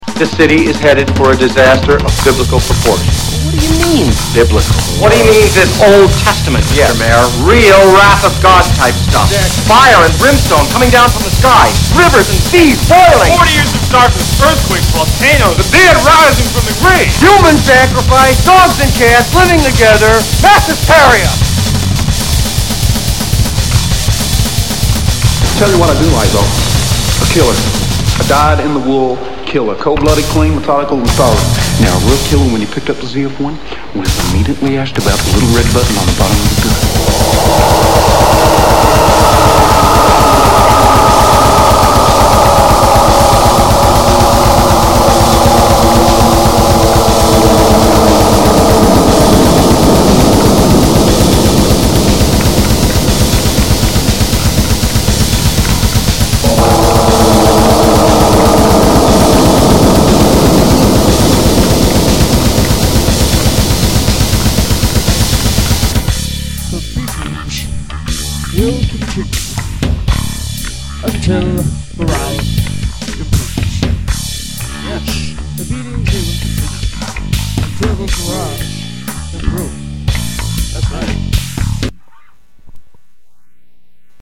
Subject description: Heavy Metal Musician At Your service!
Need a Klepacki-esque heavy and driving soundtrack to your mod?
No words.
I love that line.^^ Otherwise the track sounds a bit 'messy'.
First Off: Machinae Supremacy=Fork Yesssssssssssss. secondly: grindcore tends to get messy haha